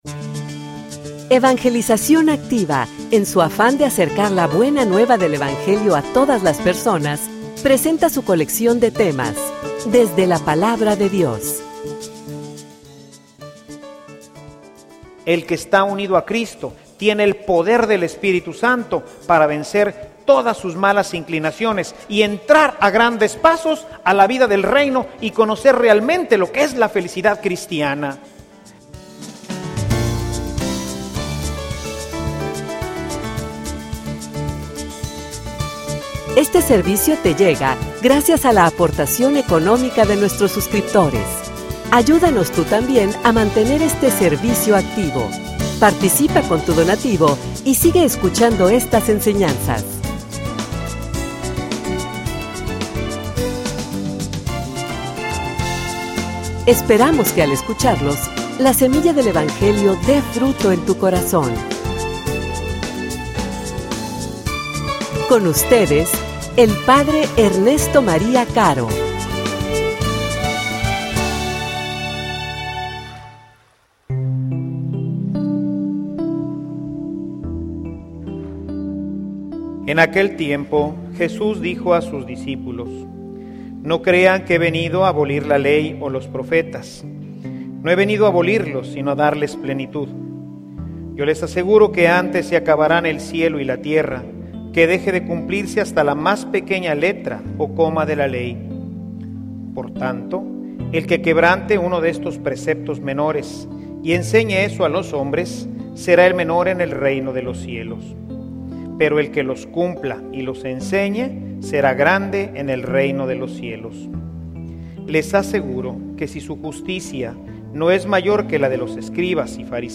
homilia_Nos_dio_la_libertad_y_la_fuerza.mp3